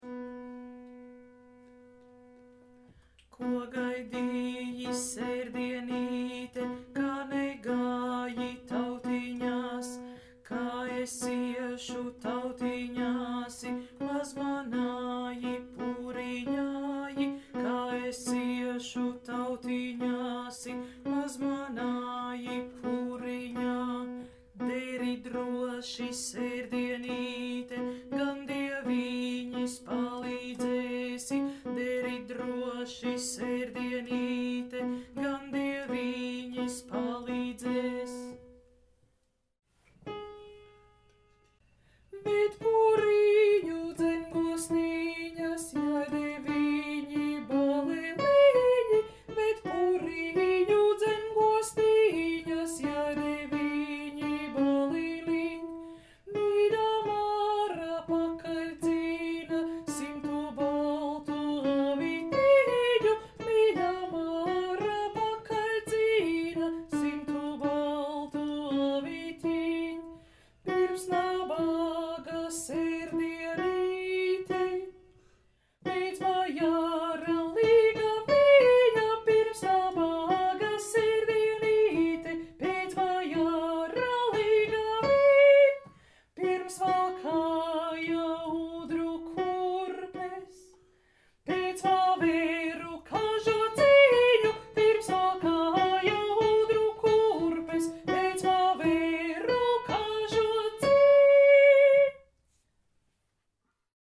Alts II